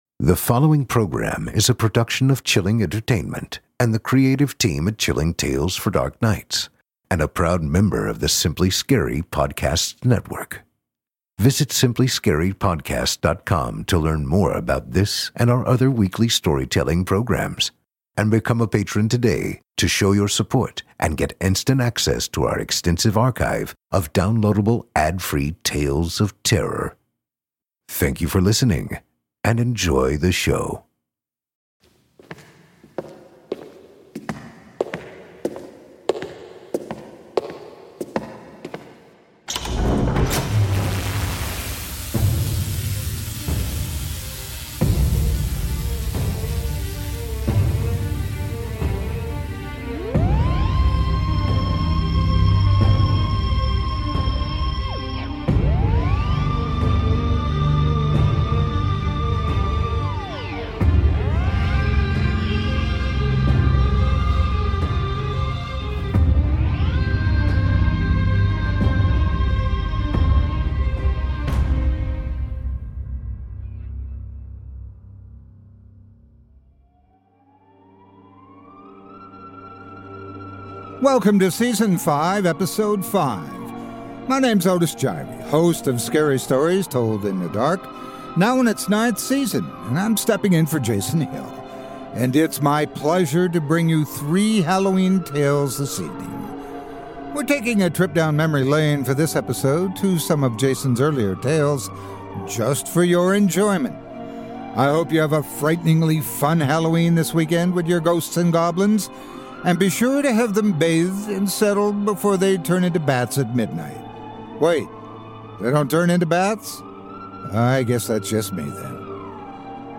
This episode features three previously released HALLOWEEN TALES! :)